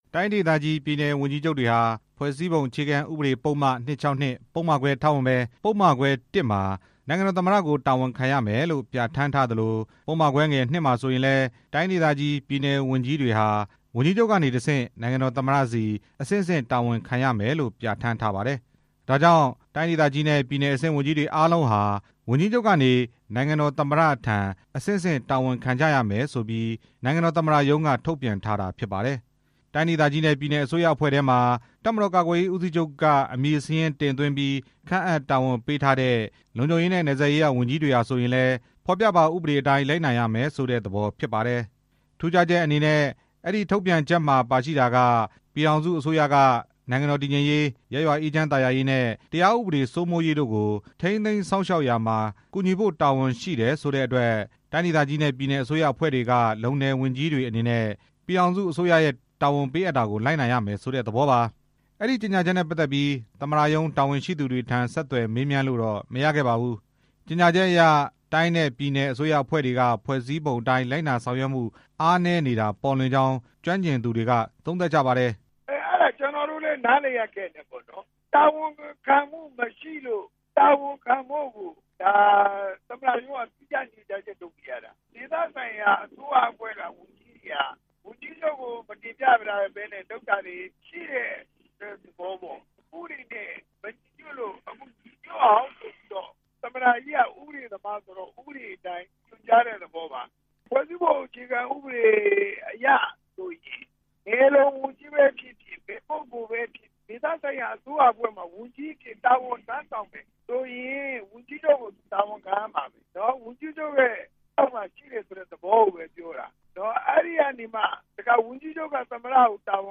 လေ့လာသူတွေ ဥပဒေရေးရာကျွမ်းကျင်သူတွေရဲ့ အမြင်တွေကို စုစည်းတင်ပြထားပါတယ်။